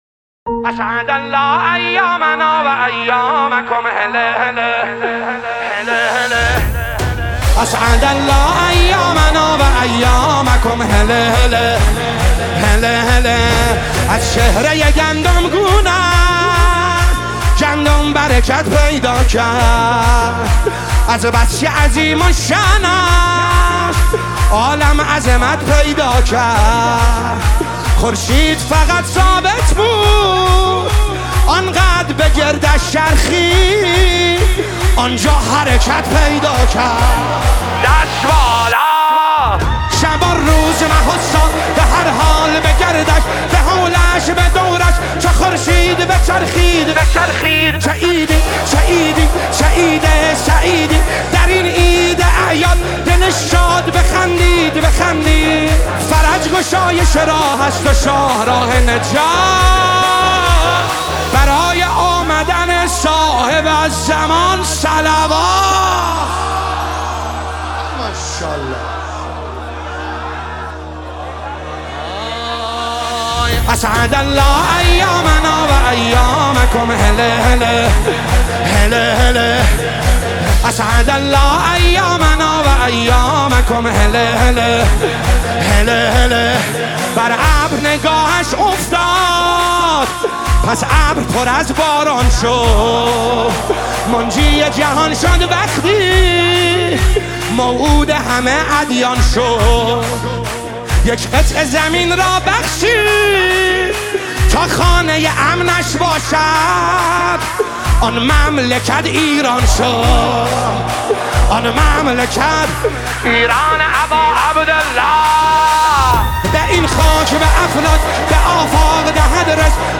سرود مولودی